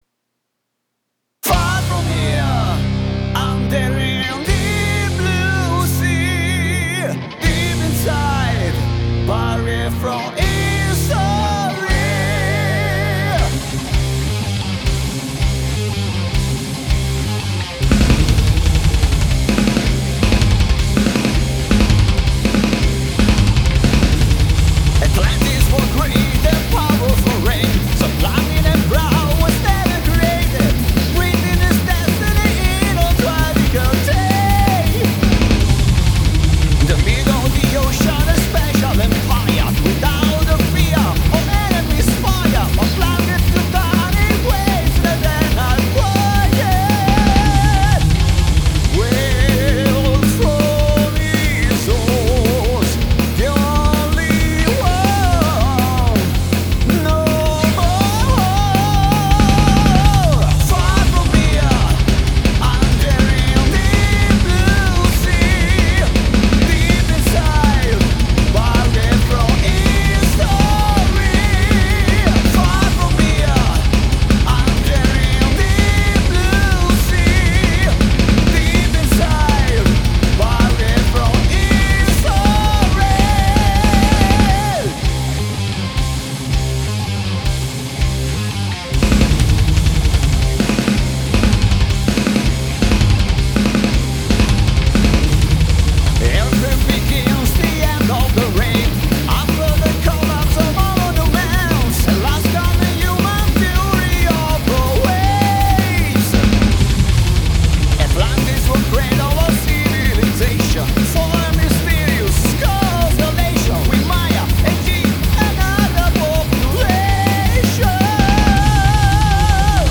♬ Thrash/Death Metal ♬ Обновление Рипа + Сканы